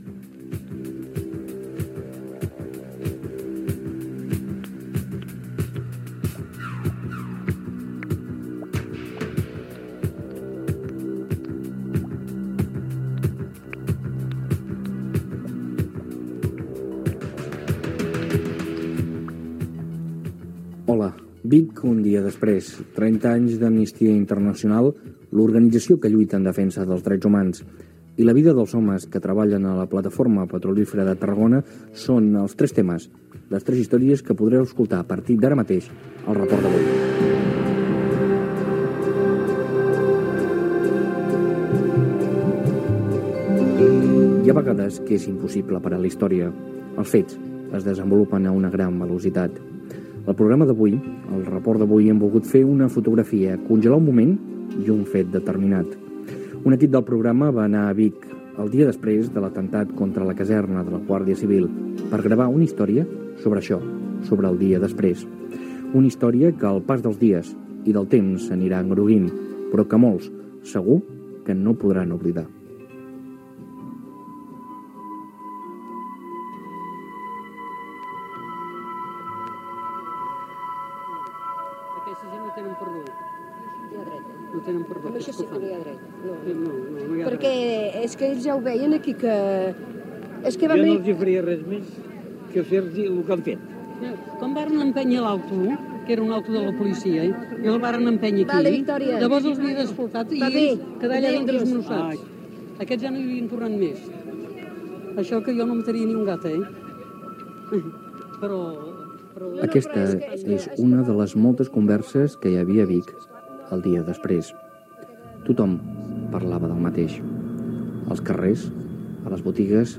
Reportatge sobre "Vic, el dia després", arran de l'atemptat d'ETA a la caserna de la Guardia Civil
Informatiu
FM